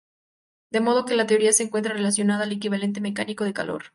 e‧qui‧va‧len‧te
/ekibaˈlente/